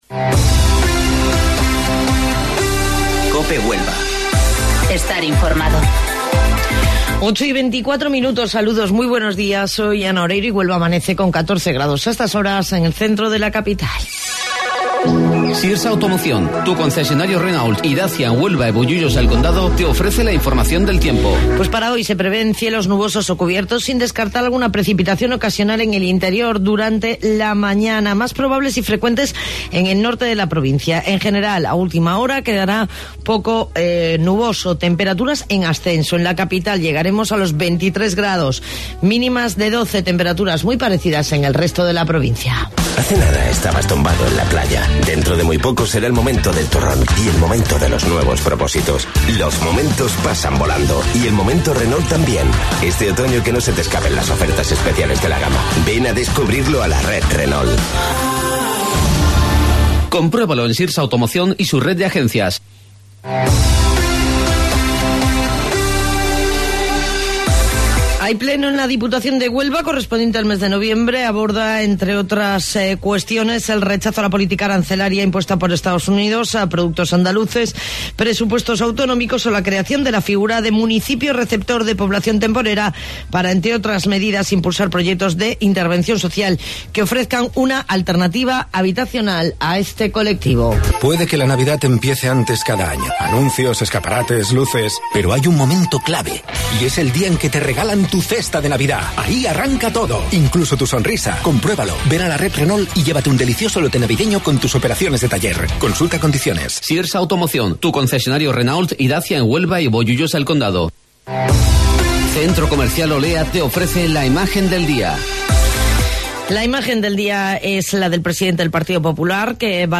AUDIO: Informativo Local 08:25 del 13 de Noviembre